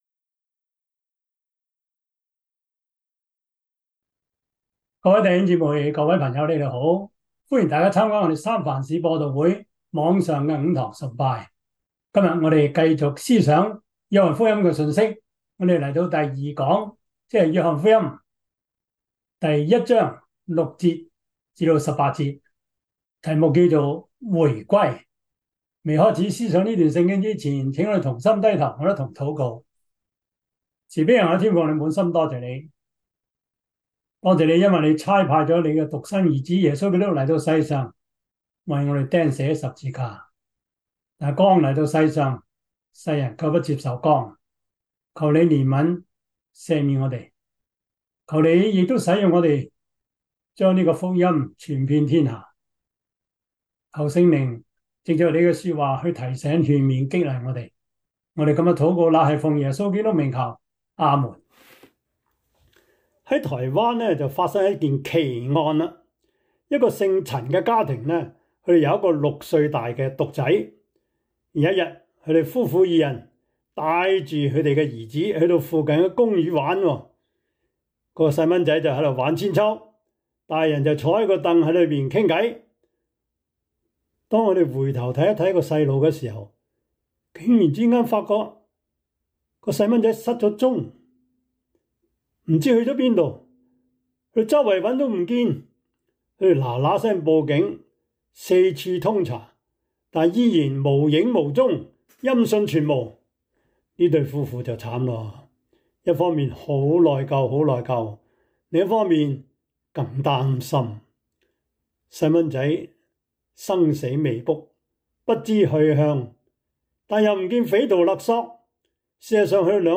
約翰福音 1:6-18 Service Type: 主日崇拜 約翰福音 1:6-18 Chinese Union Version